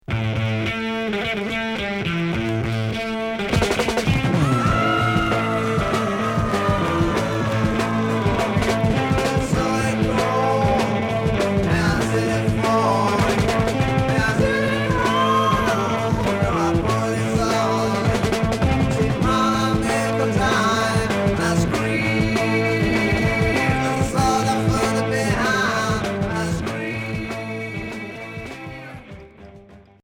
Heavy rock Progressif